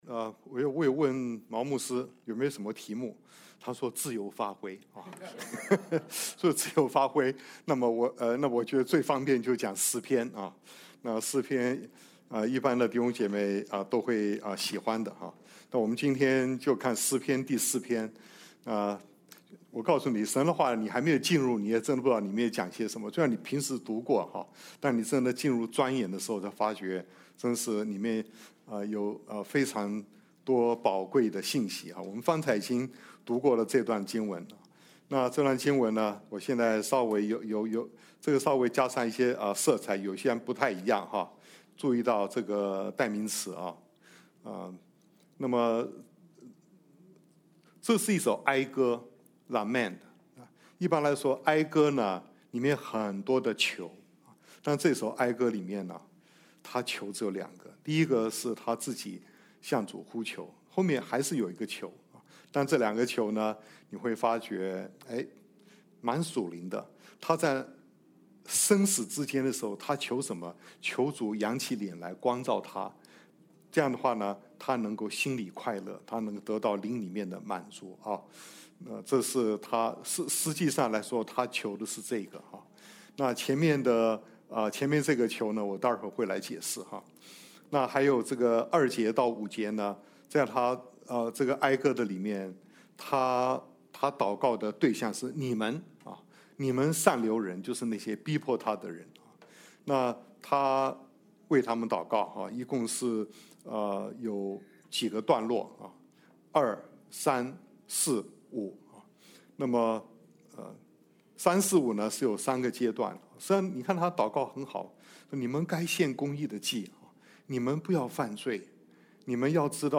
August 24, 2025 危機下的平安 Passage: Psalm 4:1; Psalm 4:2–5; Psalm 4:6–8 Service Type: 主日证道 Download Files Notes « 我心所愿 榮耀上帝聖名 » Submit a Comment Cancel reply Your email address will not be published.